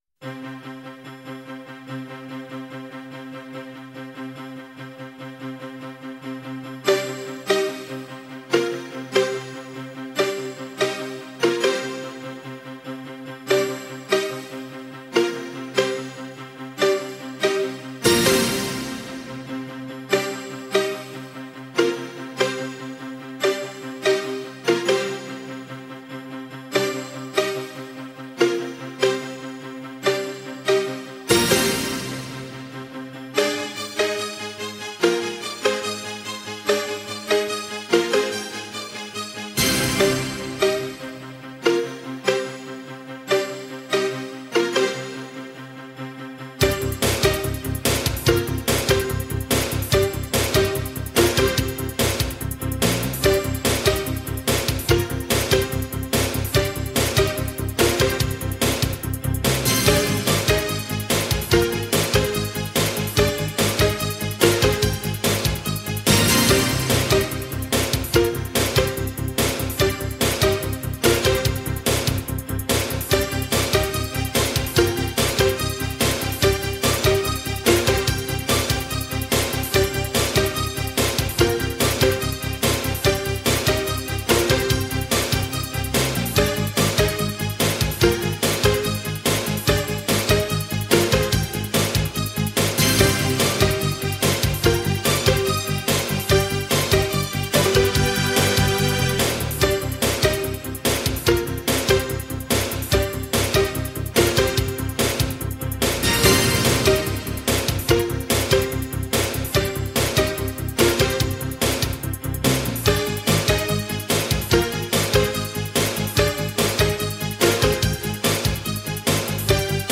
Sintonía del informativo.